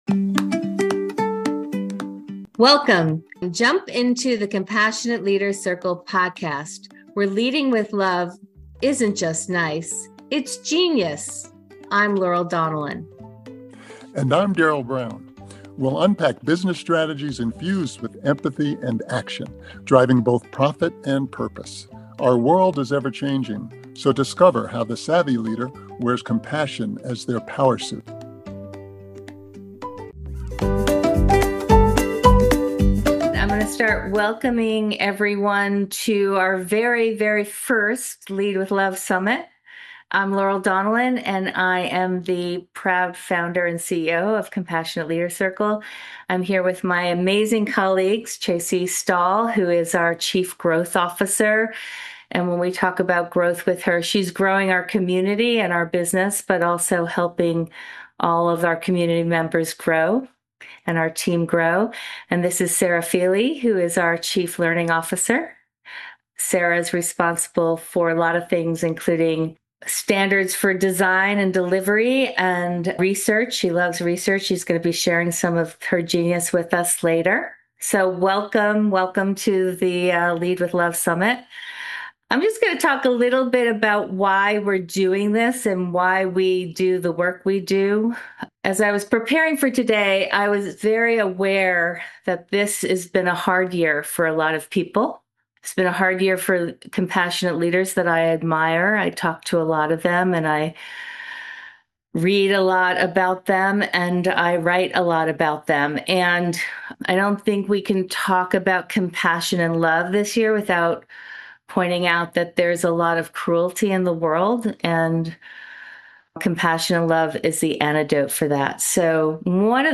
In this special episode of the Compassionate Leaders Circle podcast, we’re sharing the recording of our inaugural Lead with Love Summit where we have a conversation about what leadership requires right now.